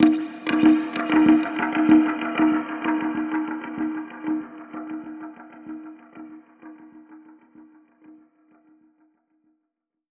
Index of /musicradar/dub-percussion-samples/95bpm
DPFX_PercHit_D_95-06.wav